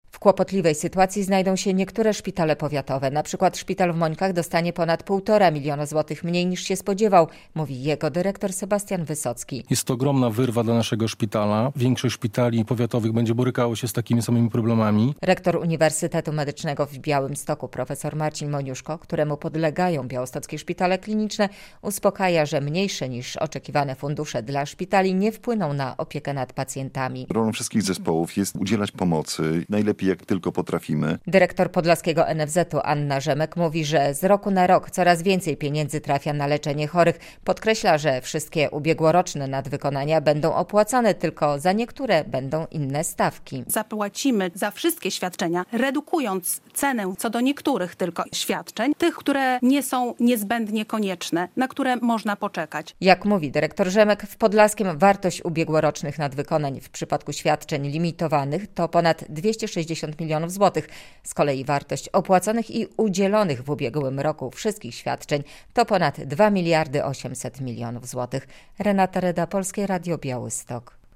W jakiej kondycji finansowej znajdą się podlaskie szpitale? - relacja